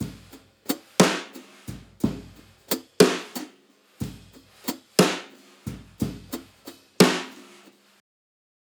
Unison Jazz - 6 - 120bpm - Tops.wav